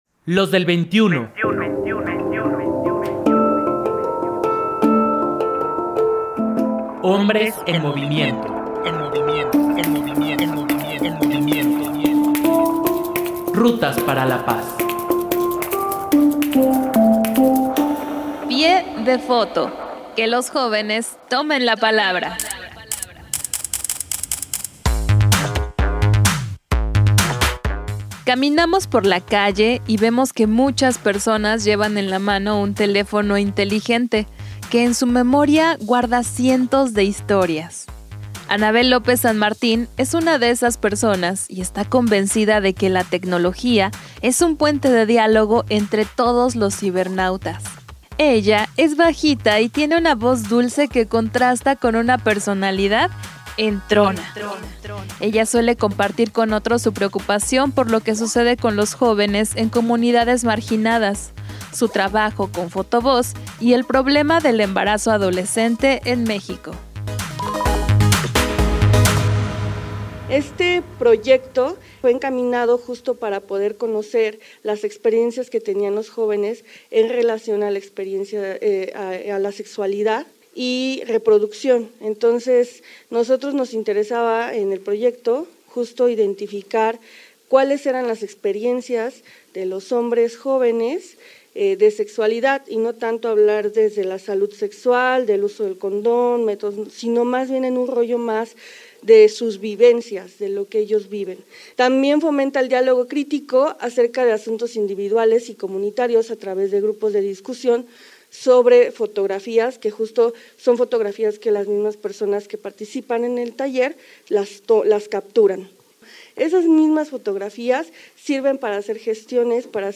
Ella es bajita y tiene una voz dulce que contrasta con una personalidad “entrona”. Ella suele compartir con otros su preocupación por lo que sucede con los jóvenes en comunidades marginadas, su trabajo con Fotovoz y el problema del embarazo adolescente en México.